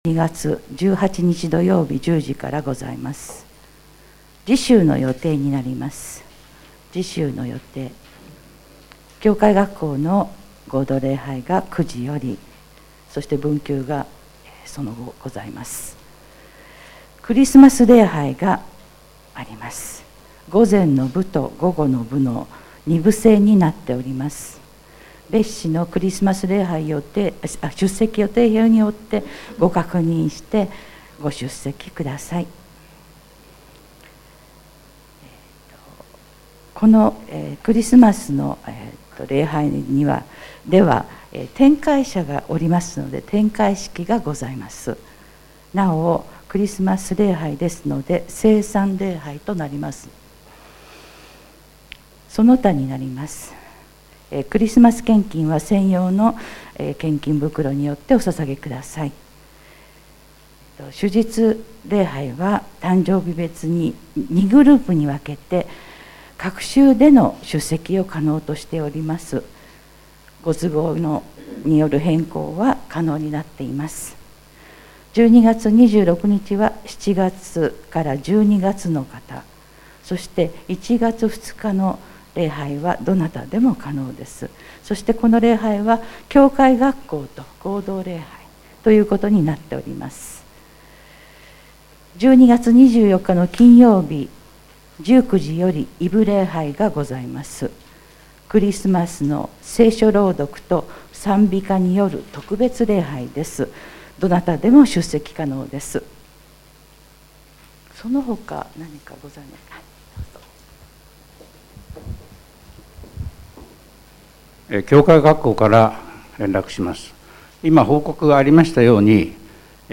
2021年12月12日礼拝音源配信はこちら
2021年12月12日礼拝音源①をダウンロードする